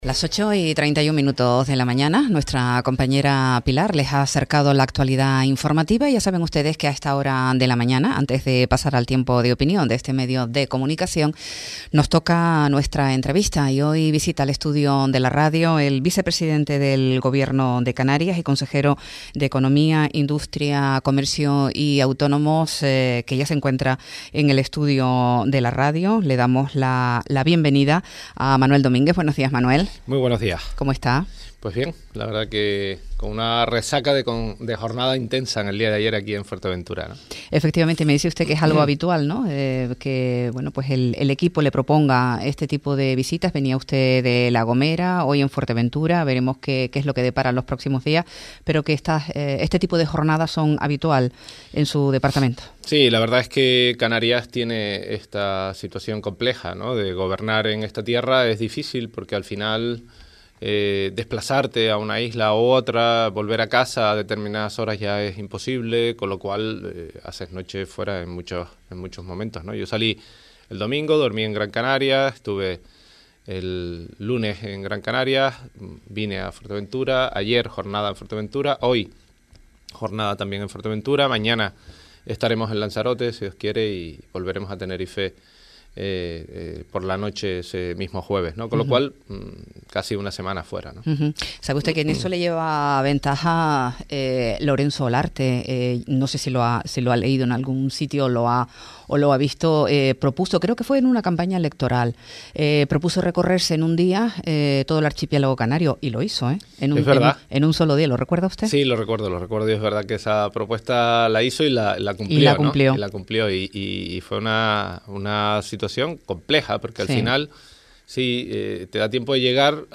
A Primera Hora, entrevista a Manuel Domínguez, vicepresidente del Gobierno de Canarias - 19.02.25 - Radio Sintonía
Entrevistas